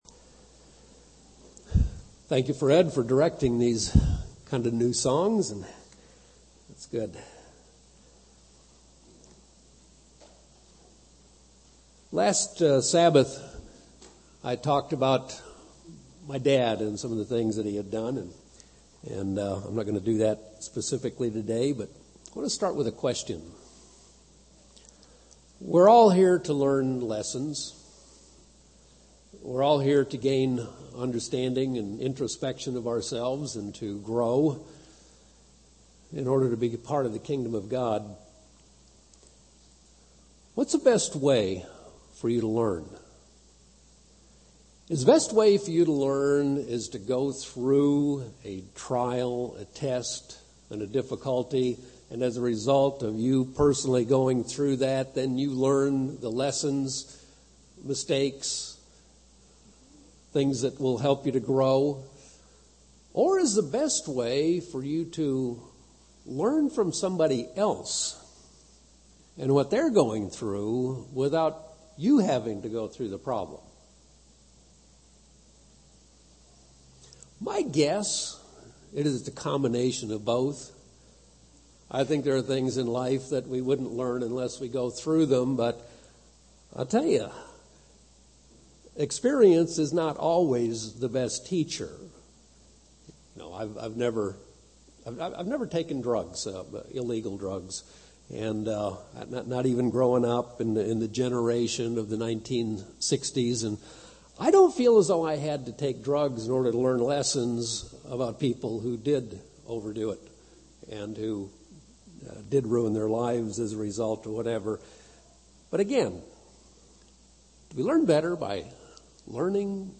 Probably both are necessary - but God gives us an opportunity to learn spiritual lessons from other people's trials. This sermon is composed of a list of lessons that one member learned as a result of her long, painful illness.